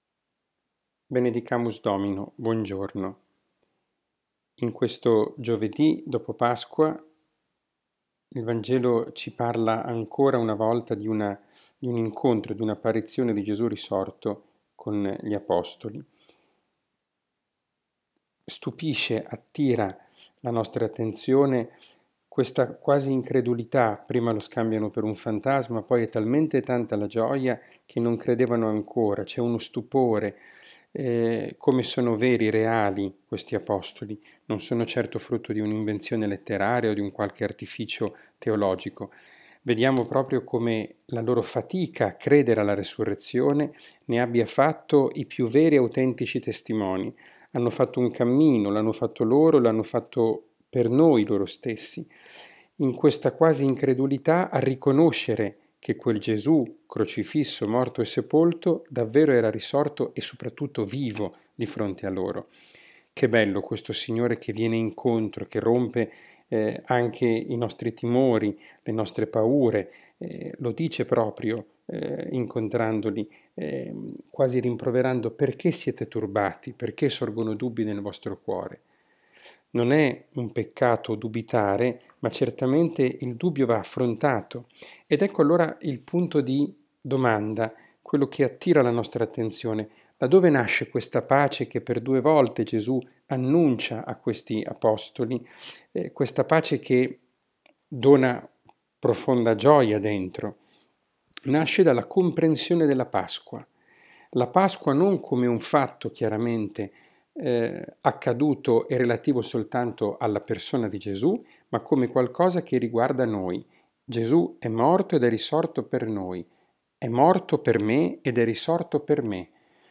catechesi, Parola di Dio, podcast